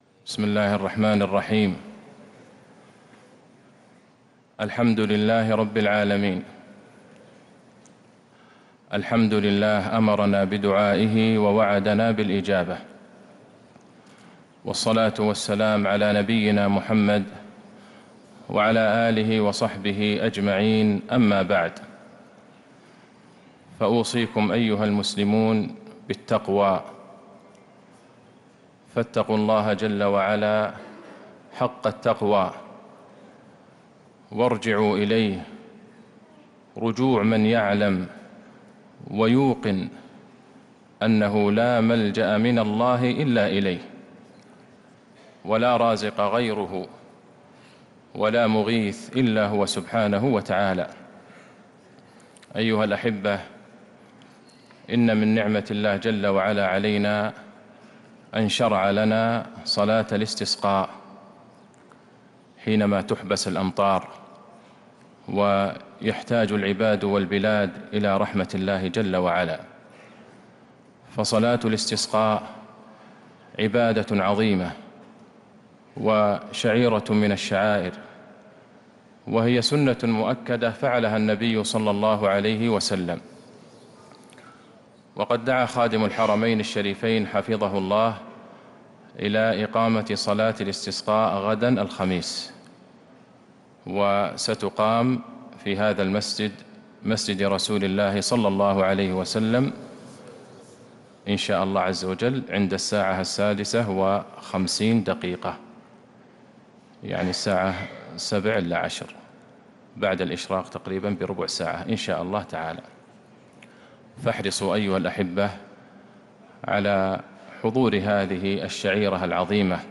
كلمة تذكيرية للشيخ عبدالله البعيجان للتذكير بصلاة الاستسقاء بعد صلاة العشاء 21 جمادى الأولى 1447هـ  > كلمات أئمة الحرم النبوي 🕌 > المزيد - تلاوات الحرمين